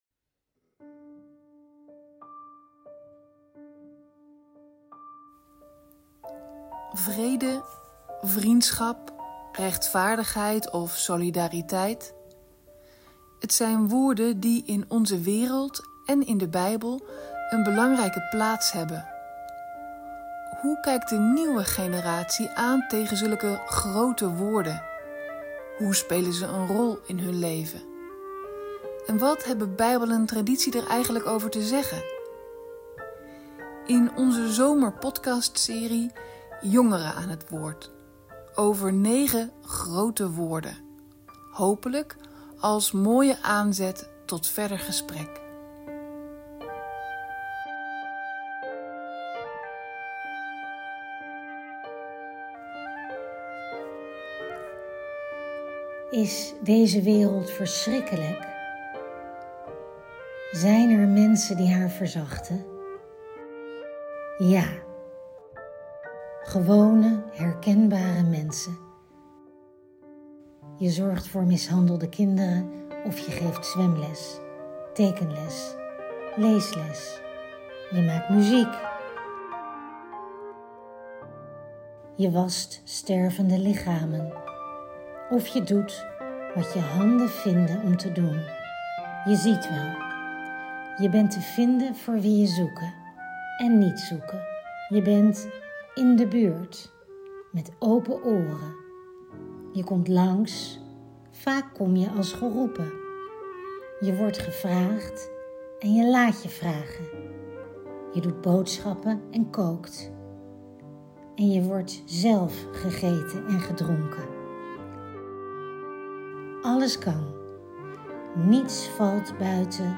Wat maakt vriendschap lastig? Een mooi gesprek.